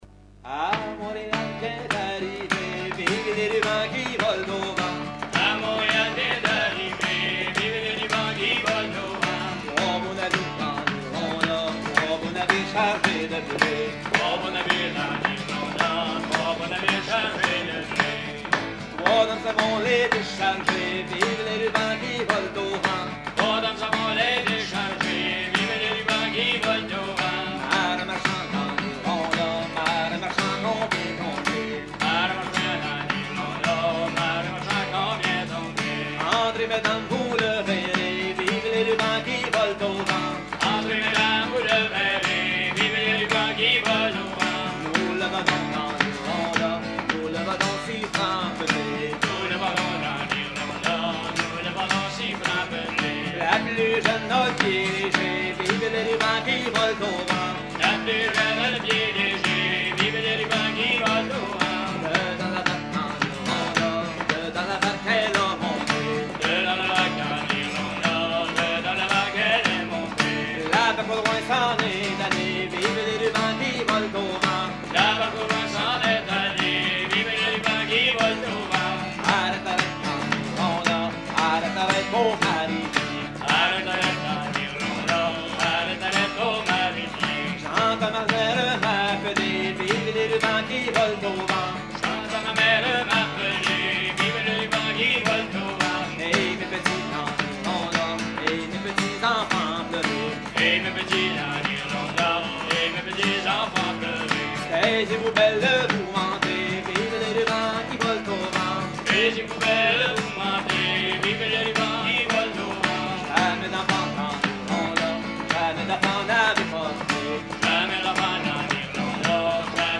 Concert à la ferme du Vasais
Pièce musicale inédite